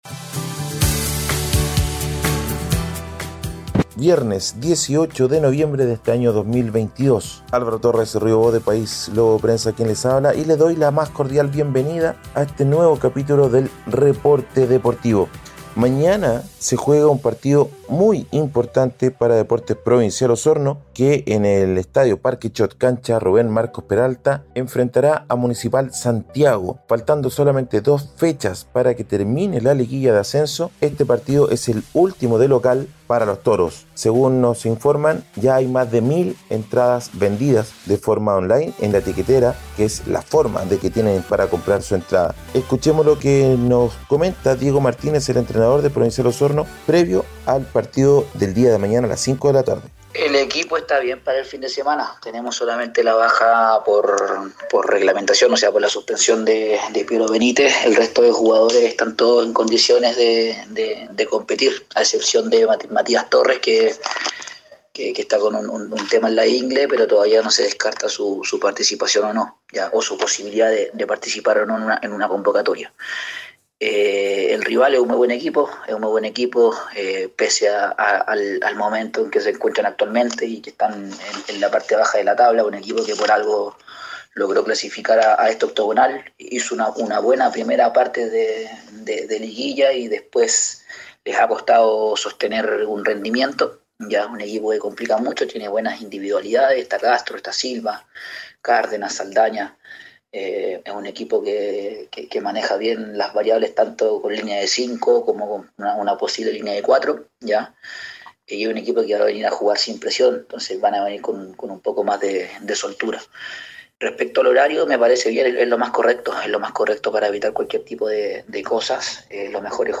Reporte Deportivo ▶ Podcast 18 de noviembre de 2022